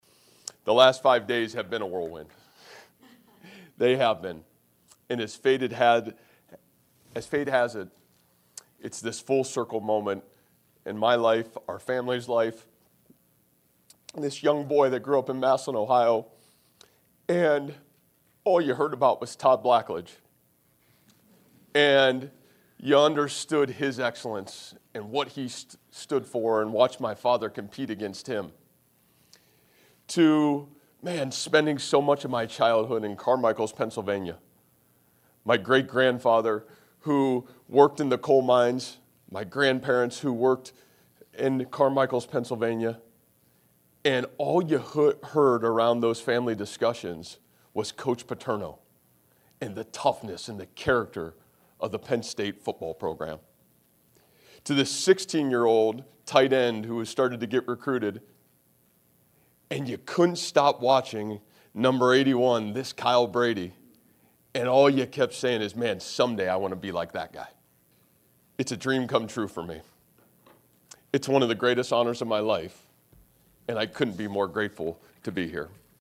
(University Park, PA) — On Monday morning — an hour after Jimmy Rogers was introduced as the new football head coach at Iowa St. — Penn St. had a press conference to introduce Matt Campbell as their new football head coach.
Campbell got emotional when he got to the podium saying it was a dream come true to be Head Coach Penn St. football